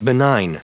Prononciation du mot benign en anglais (fichier audio)
Prononciation du mot : benign